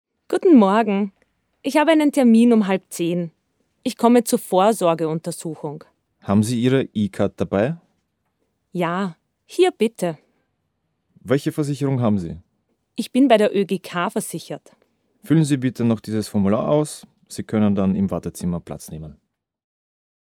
„Gespräch a"
DLM_Dialog_1.mp3